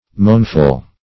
Moanful \Moan"ful\, a.
moanful.mp3